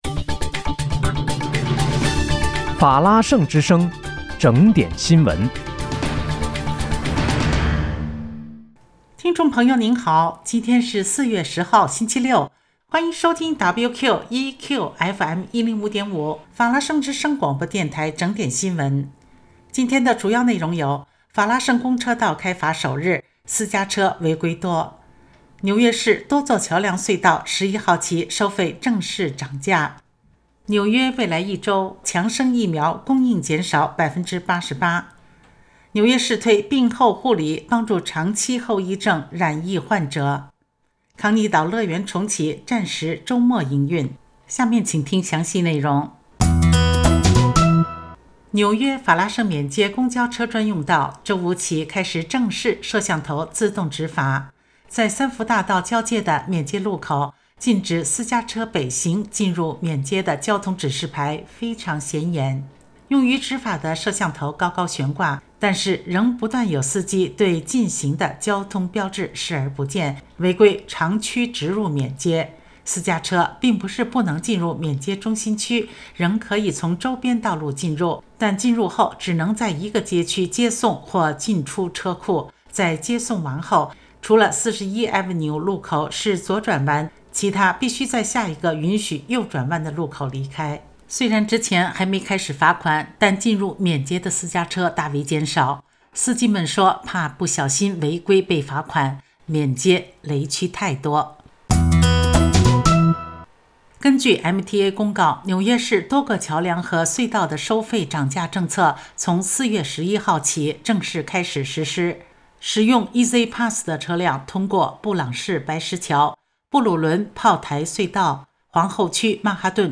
4月10日（星期六）纽约整点新闻